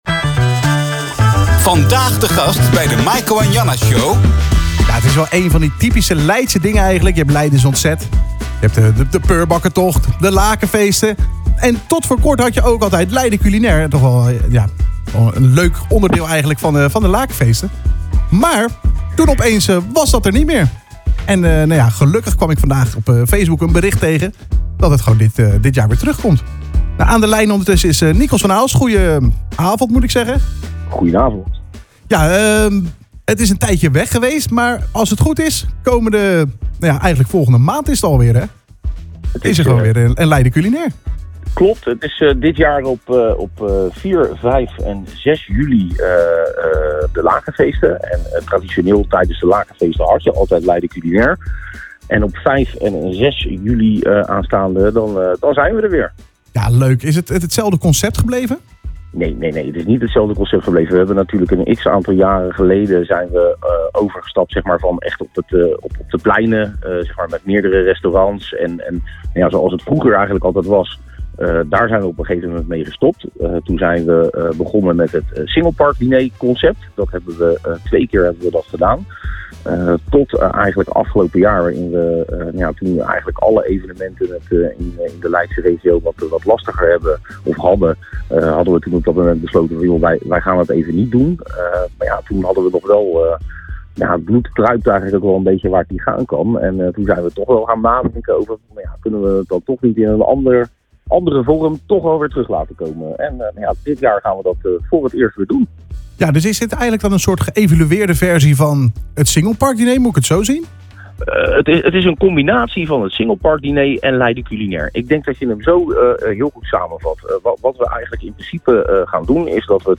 aan de lijn